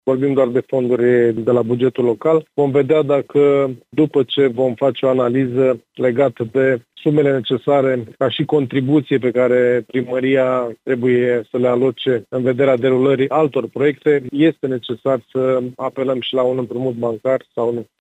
Primăria va aloca fondurile necesare pentru reamenajare de la bugetul local, după cum a mai declarat primarul Adrian Niță: ”Vorbim doar de fonduri de la bugetul local.